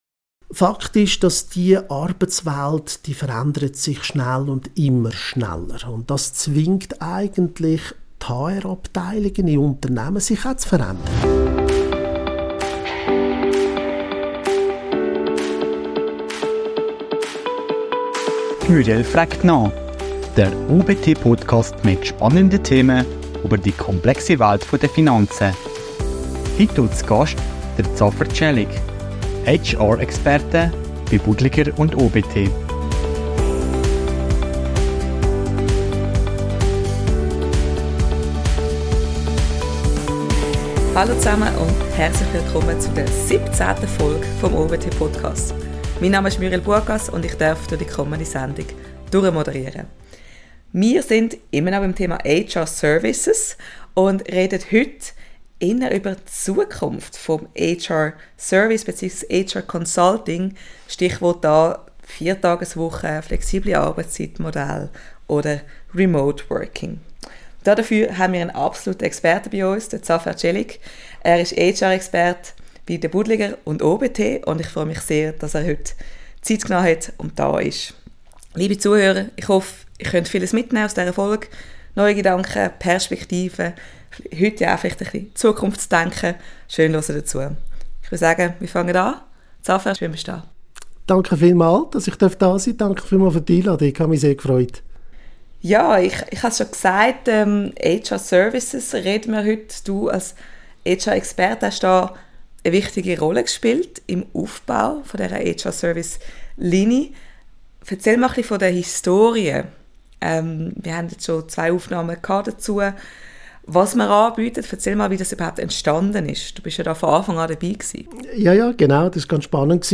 Freu dich auf eine vielseitige Diskussion, die dir neue Perspektiven auf die Arbeitswelt eröffnen wird.